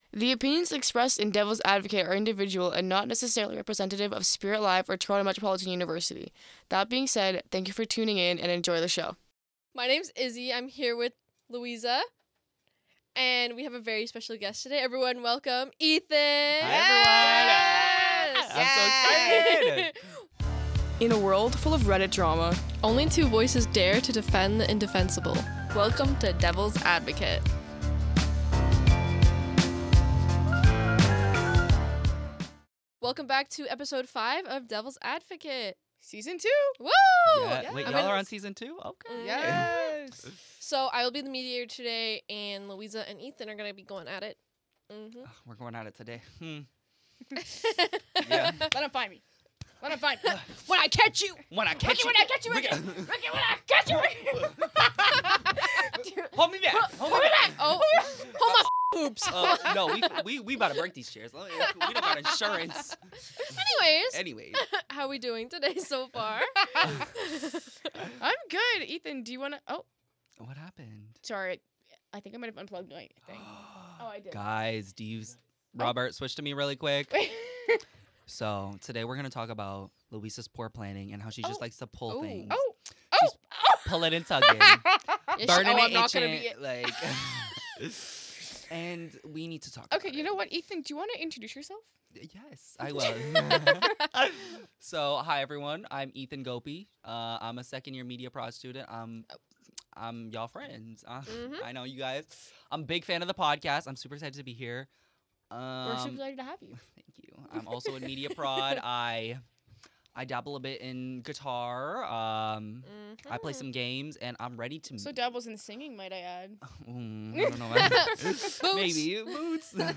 Using Reddit “Am I the Asshole?” posts, one host has to defend the person while the other argues against them, no matter their real opinion. It’s part comedy, part debate, and part chaos.